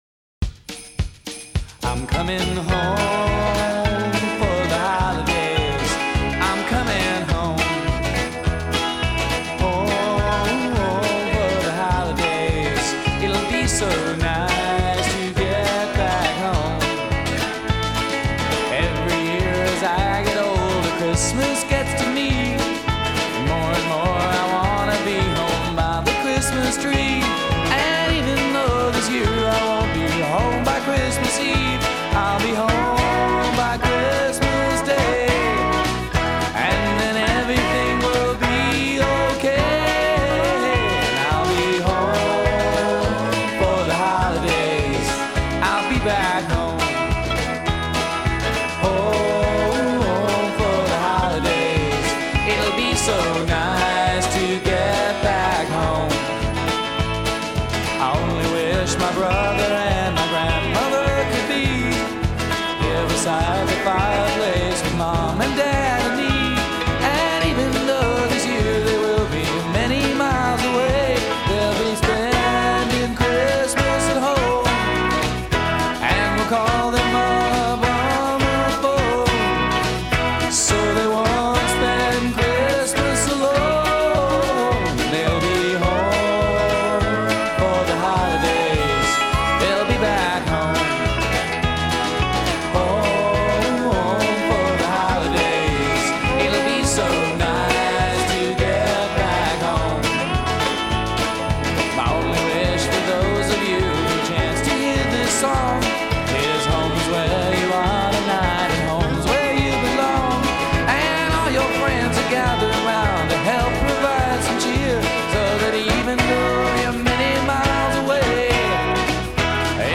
non-Traditional Christmas music